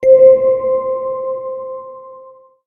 chime.wav